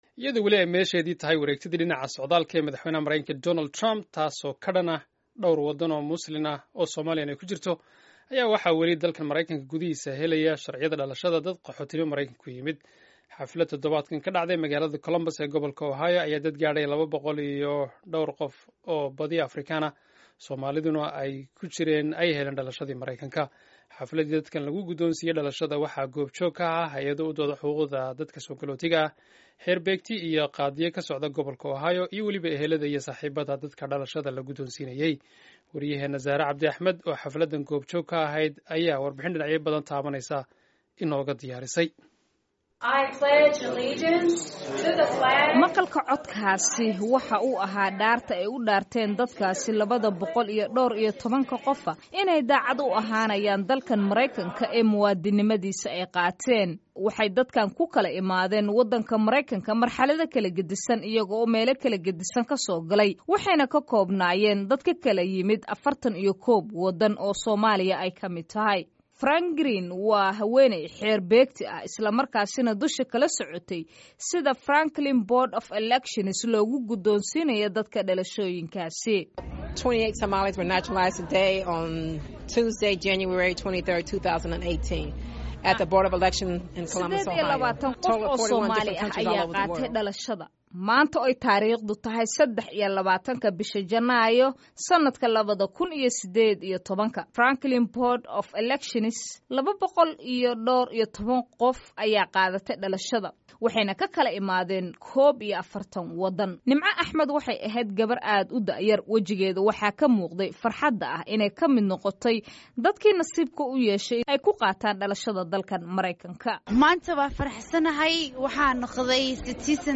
oo xafladdan goob joog ka ahayd ayaa warbixin dhinacyo badan taabanaysa inooga diyaarisey.